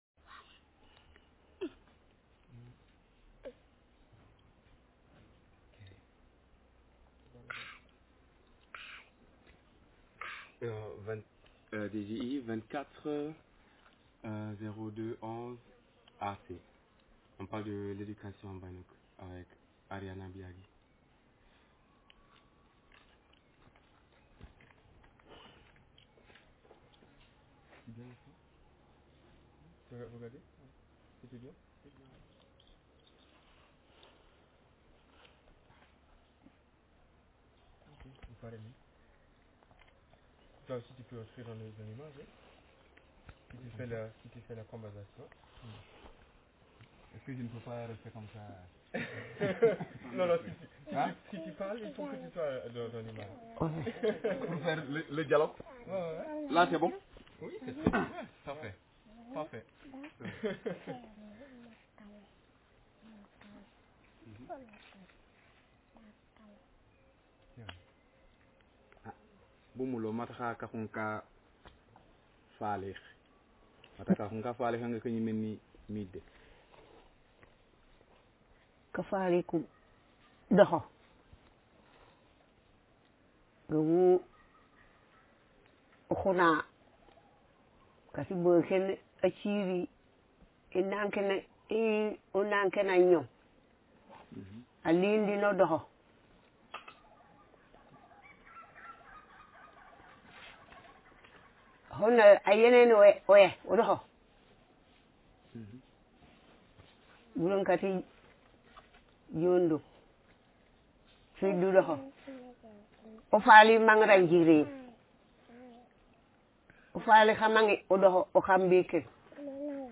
Speaker sex f/m Text genre conversation